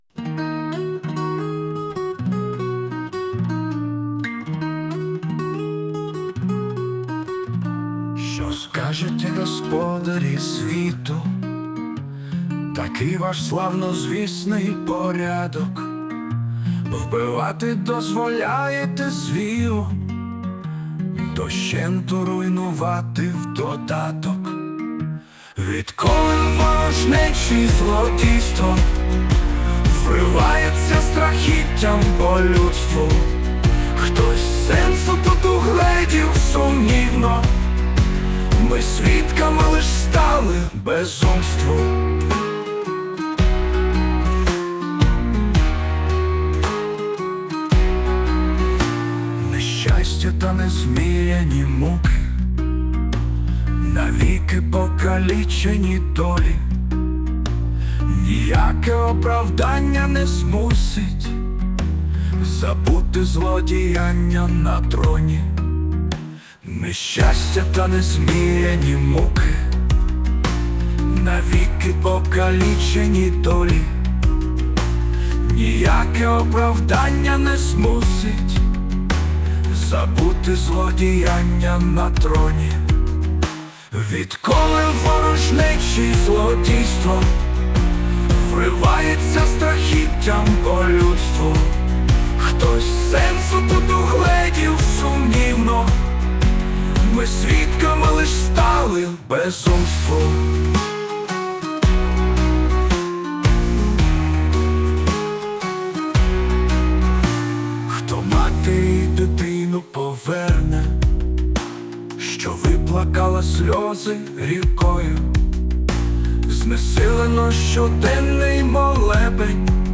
Музична композиція створена за допомогою SUNO AI
СТИЛЬОВІ ЖАНРИ: Ліричний
Не ті ритми в мелодії.